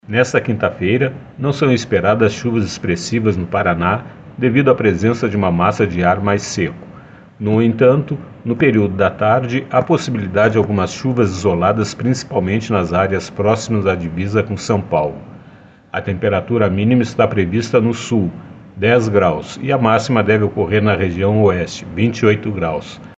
Ouça o que diz o meteorologista do Simepar